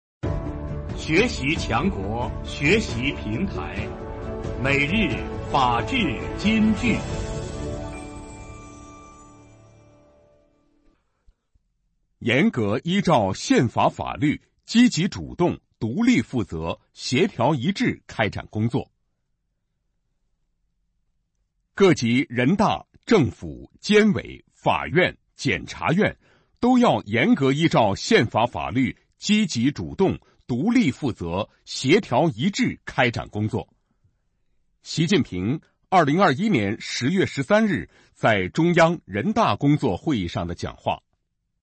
每日法治金句（朗读版）|严格依照宪法法律积极主动、独立负责、协调一致开展工作 _ 学习宣传 _ 福建省民政厅